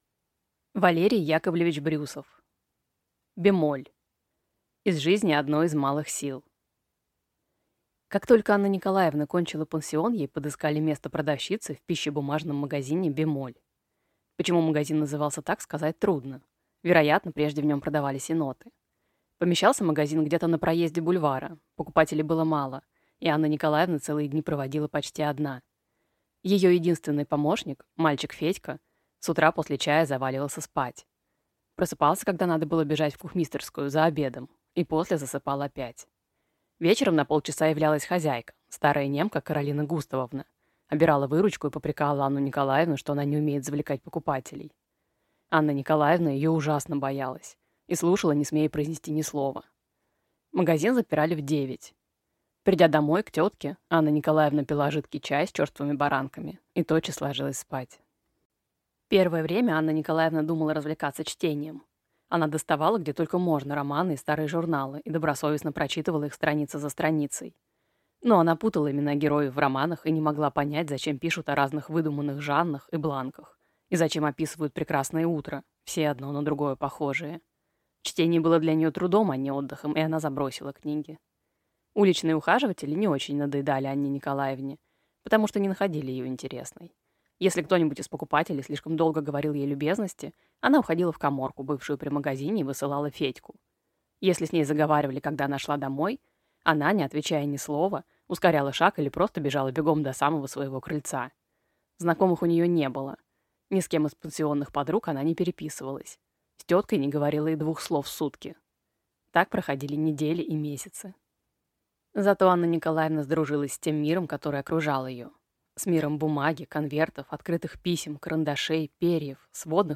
Аудиокнига Бемоль | Библиотека аудиокниг